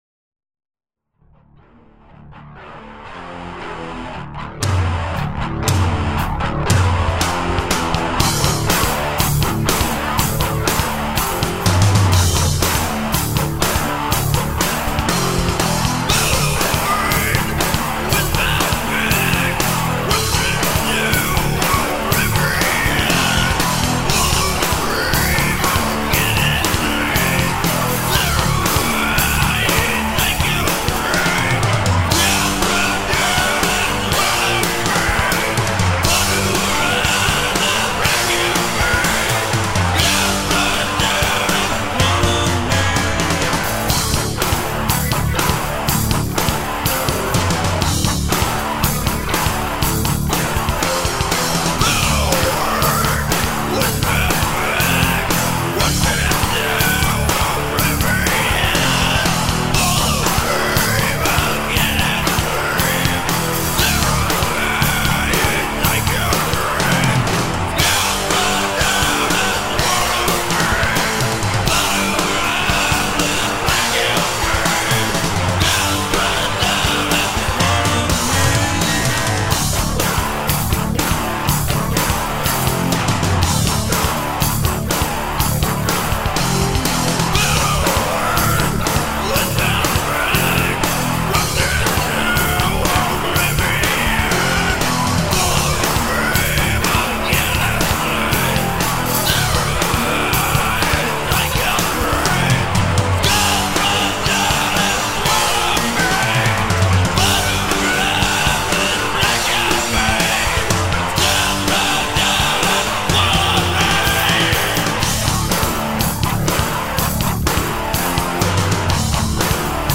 putting a somewhat sinister appeal to itself